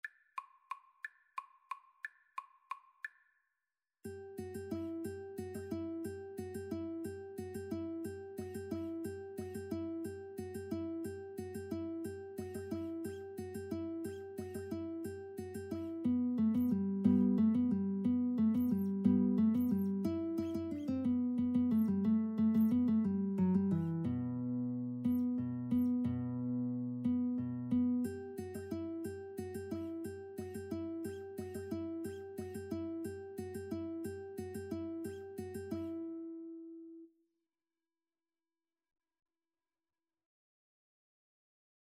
Free Sheet music for Guitar Duet
E minor (Sounding Pitch) (View more E minor Music for Guitar Duet )
3/4 (View more 3/4 Music)